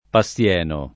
Passieno [ pa SSL$ no ]